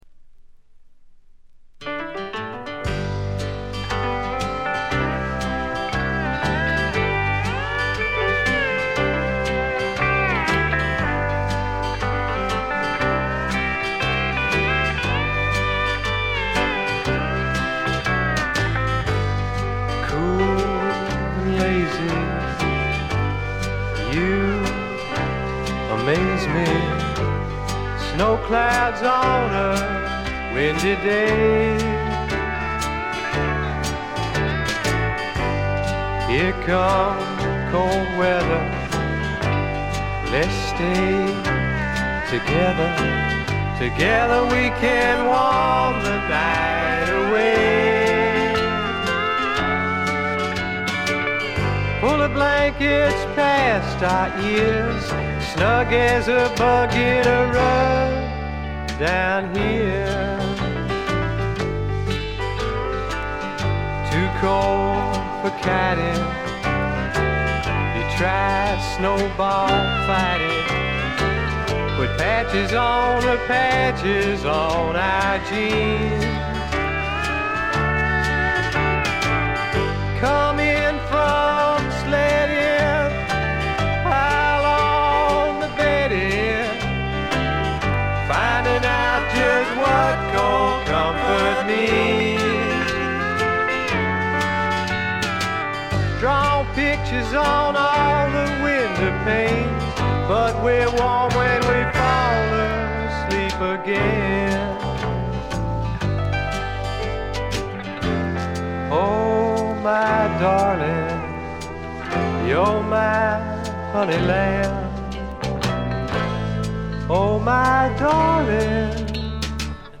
ところどころでチリプチ。
カントリー風味、オールド・ロックンロールを元にスワンプというには軽い、まさに小粋なパブロックを展開しています。
試聴曲は現品からの取り込み音源です。